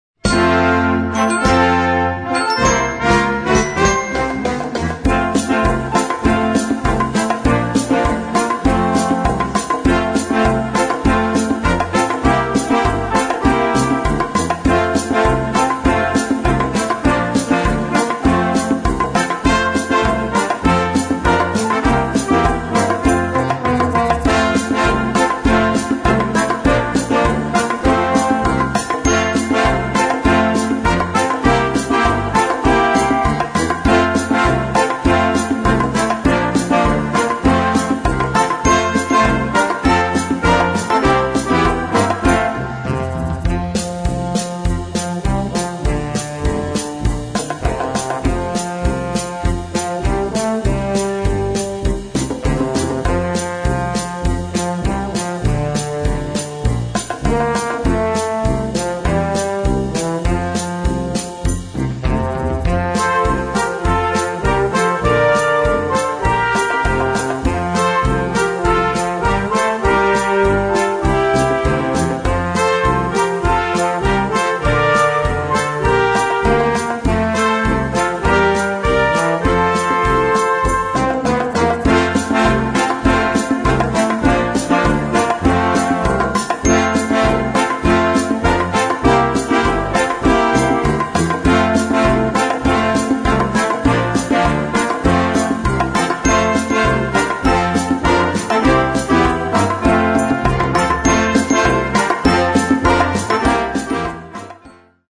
Noten für flexibles Jugend Ensemble, 4-stimmig + Percussion.